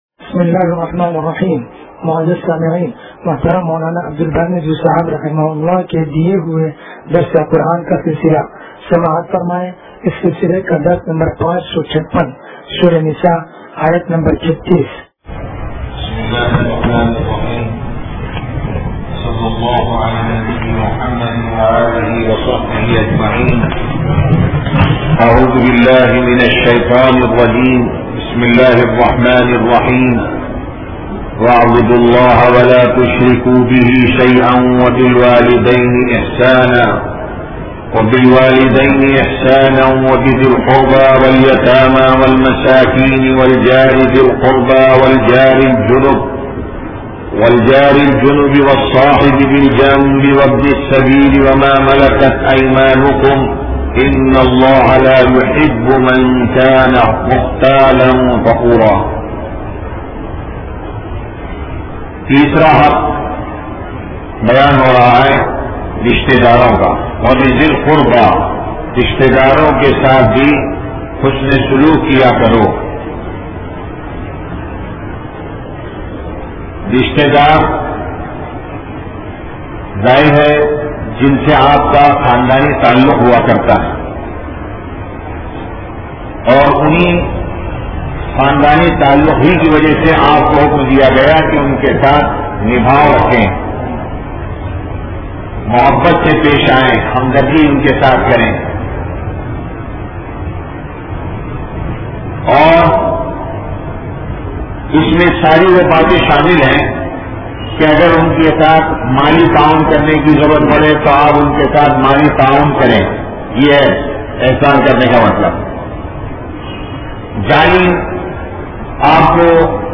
درس قرآن نمبر 0556
درس-قرآن-نمبر-0556.mp3